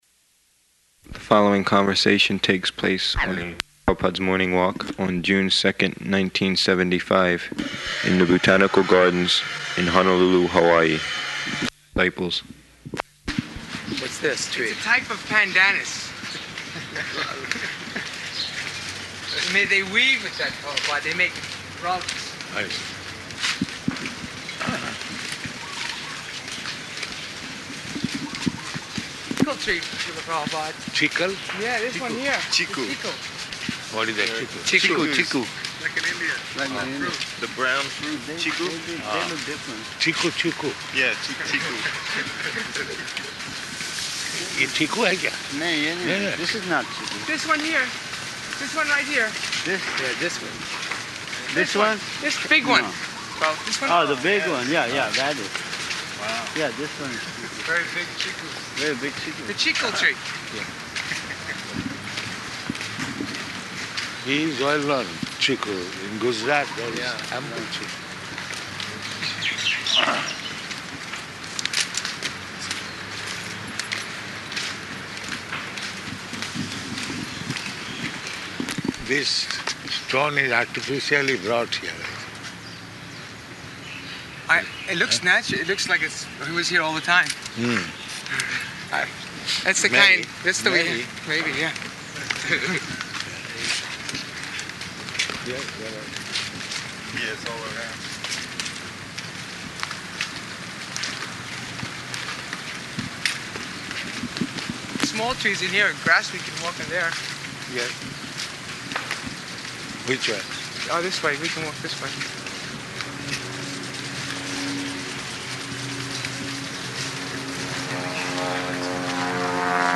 Morning Walk in the Botanical Gardens
Type: Walk
Location: Honolulu